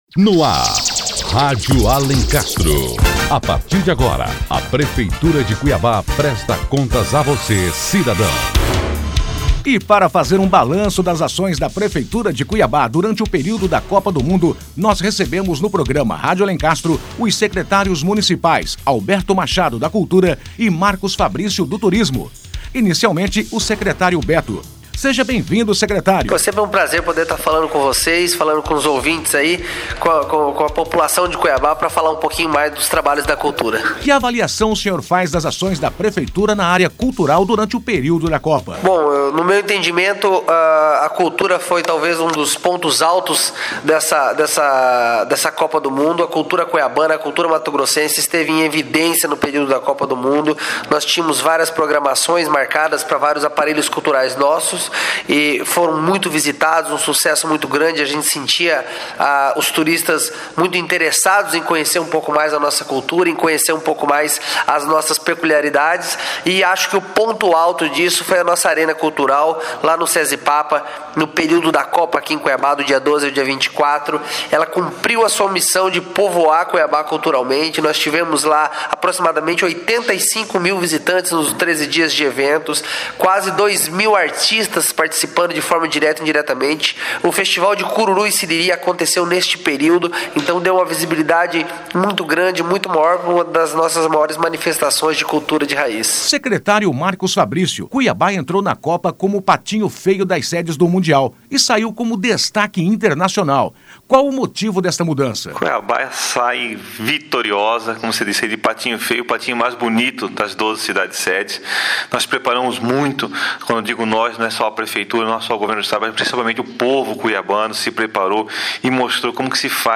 Notícias / 111º Programa 14 de Julho de 2014 17h48 Cuiabá na Copa do Mundo Ouça as entrevistas com o Secretário Municipal de Cultura, Alberto Machado, e com o Secretário Municipal de Turismo, Marcus Fabrício, e fique por dentro das ações da Prefeitura de Cuiabá durante a Copa do Mundo.